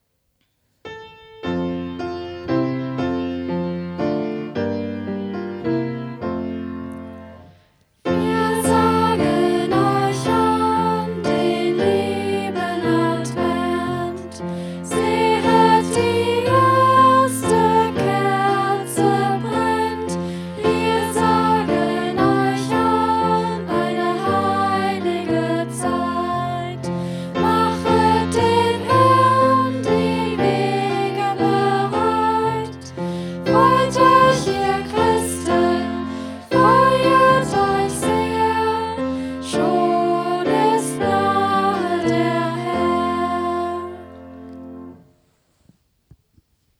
Lied:
am Flügel